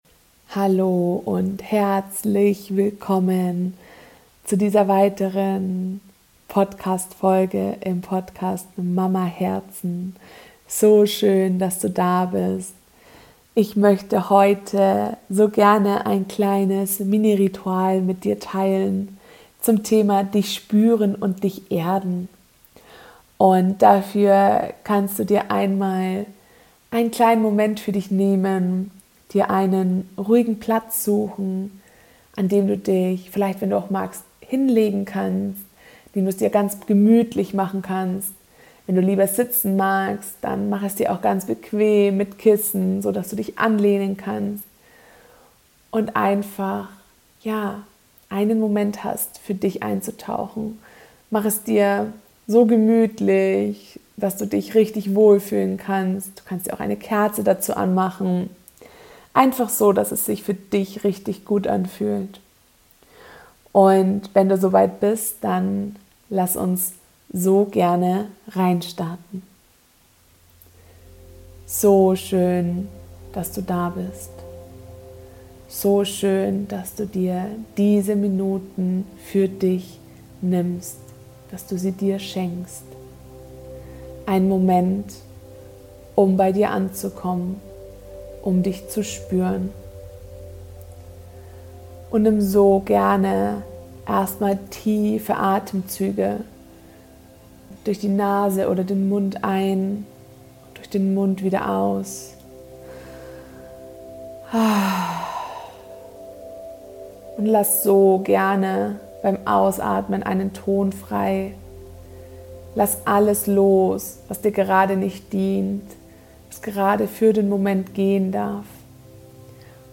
#3: Mini-Meditation: Dich spüren und Dich erden ~ MamaHERZEN - feinfühlig & stark Podcast
Beschreibung vor 2 Monaten Liebe wundervolle Mama, in dieser Podcastfolge erwartet Dich ein kleines Mini-Ritual, eine kleine Meditation, um Dich zu spüren, bei Dir anzukommen, Dich zu erden - einfach um Dir einen Moment für Dich zu schenken. Wenn Du die Folge öfter hören möchtest und direkt bei der Meditation starten willst (ohne die Einführung), dann kannst Du auf Minute 1:11 vorspulen.